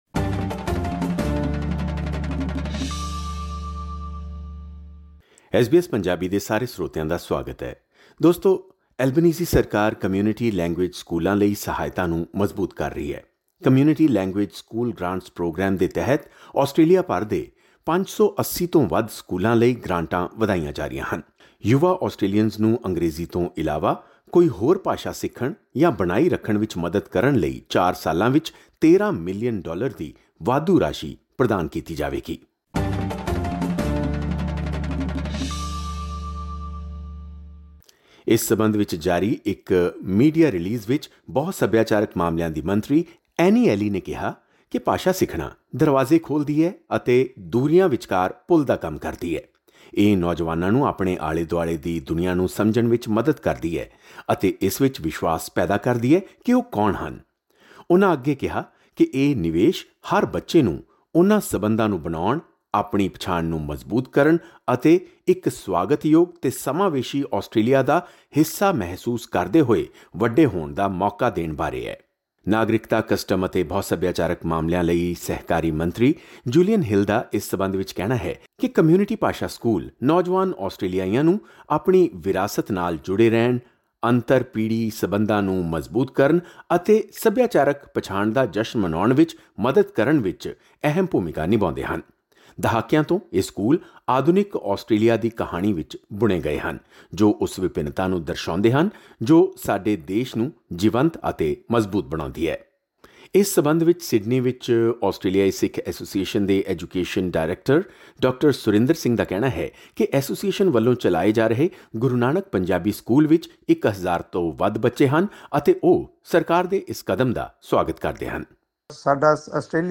ਕਮਿਊਨਿਟੀ ਲੈਂਗੂਏਜ ਸਕੂਲ ਗ੍ਰਾਂਟਸ ਪ੍ਰੋਗਰਾਮ ਦੇ ਤਹਿਤ ਆਸਟ੍ਰੇਲੀਆ ਭਰ ਦੇ 580 ਤੋਂ ਵੱਧ ਸਕੂਲਾਂ ਲਈ ਗ੍ਰਾਂਟਾਂ ਵਧਾਈਆਂ ਜਾ ਰਹੀਆਂ ਹਨ ਜਿਸ ਲਈ ਚਾਰ ਸਾਲਾਂ ਲਈ 13 ਮਿਲੀਅਨ ਡਾਲਰ ਦੀ ਵਾਧੂ ਰਾਸ਼ੀ ਪ੍ਰਦਾਨ ਕੀਤੀ ਜਾਏਗੀ। ਐਲਬਨੀਜ਼ੀ ਸਰਕਾਰ ਦੇ ਇਸ ਫੈਸਲੇ ਦਾ ਭਾਈਚਾਰੇ ਵਿੱਚ ਪੰਜਾਬੀ ਸਕੂਲ ਚਲਾਉਣ ਵਾਲੀਆਂ ਸੰਸਥਾਵਾਂ ਨੇ ਸੁਆਗਤ ਕੀਤਾ ਹੈ। ਇਸ ਸਬੰਧੀ ਖਾਸ ਰਿਪੋਰਟ ਇਸ ਪੌਡਕਾਸਟ ਰਾਹੀਂ ਸੁਣੋ।